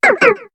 Cri de Polarhume dans Pokémon HOME.